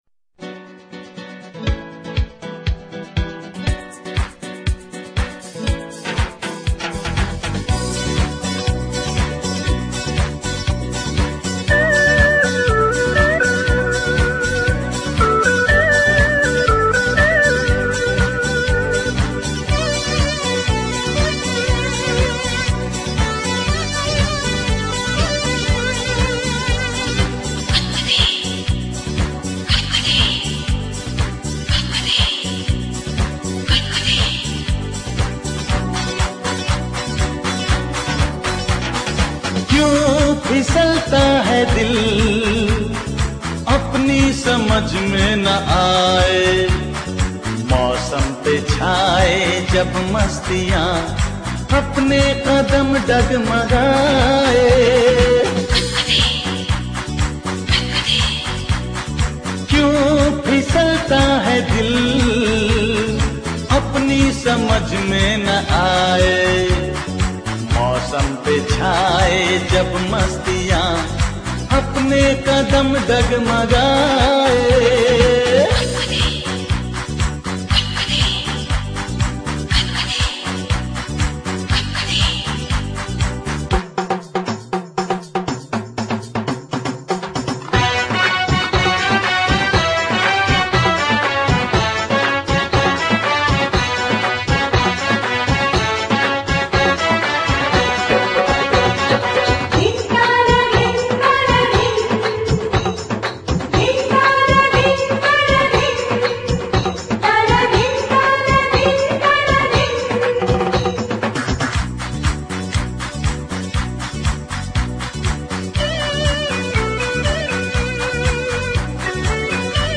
Hindi Movie